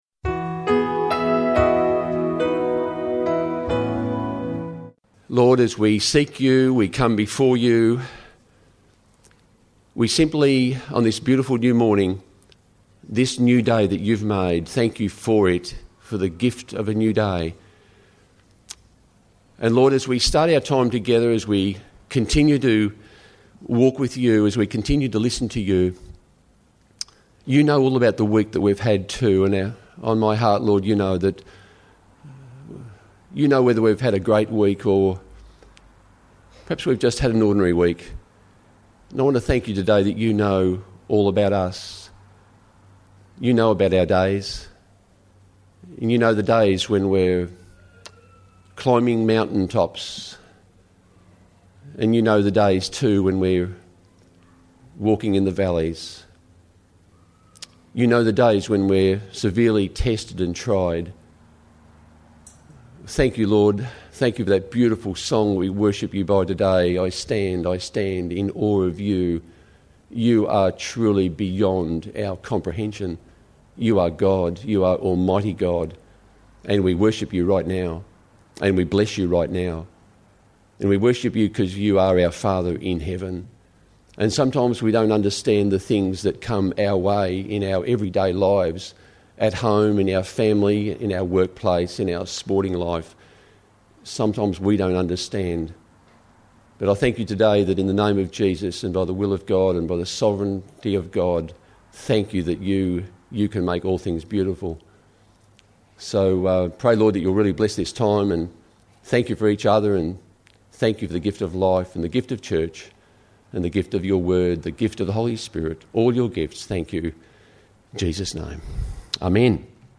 His prayer is a morning prayer.